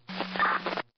radio_effect.ogg